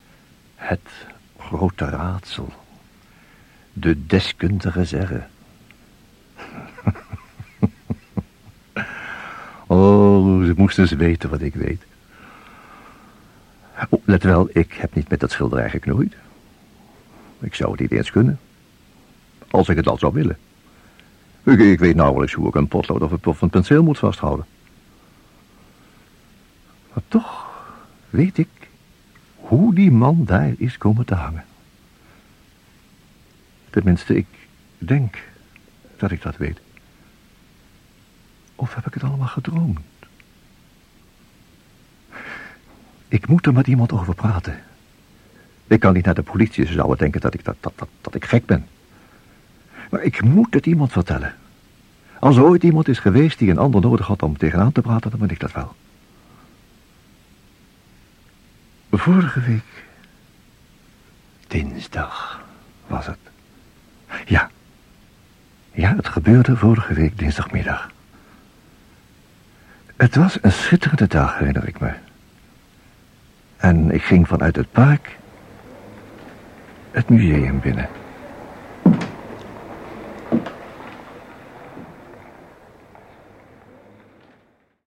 Jan Borkus: Fragmenten - Geronimo hoorspelen
Jan Borkus als Gordon.